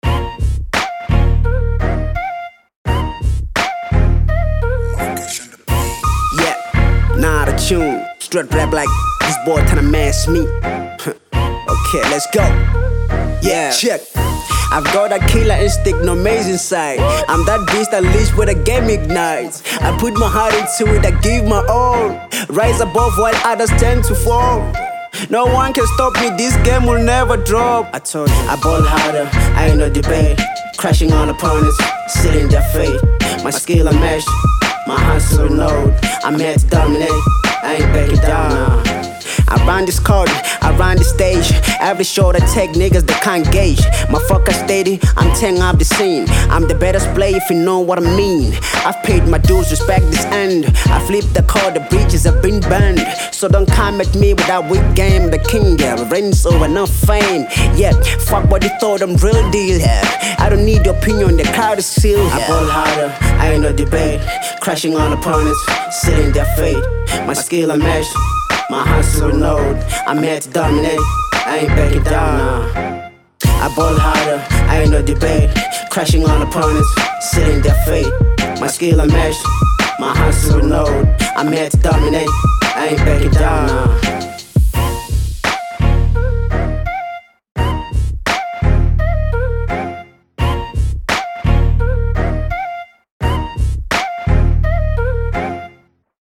01:36 Genre : Hip Hop Size